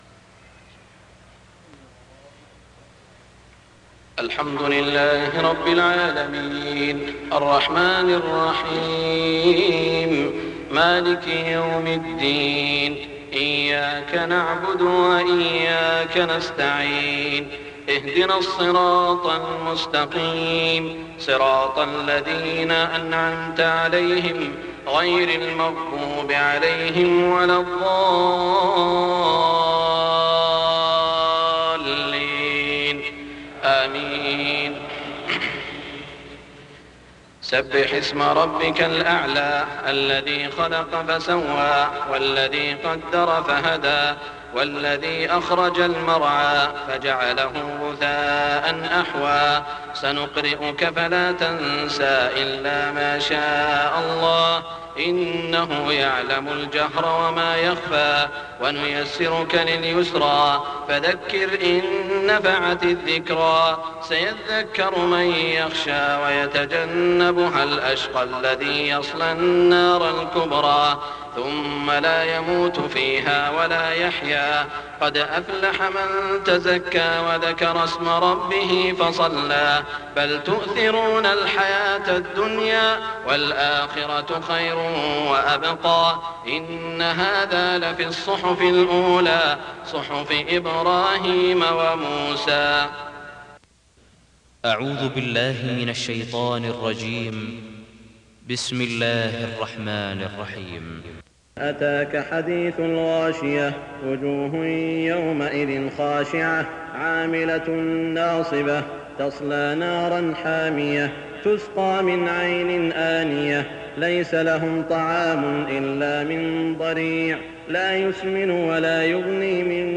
صلاة الجمعة 6-4-1424هـ سورتي الأعلى الغاشية > 1424 🕋 > الفروض - تلاوات الحرمين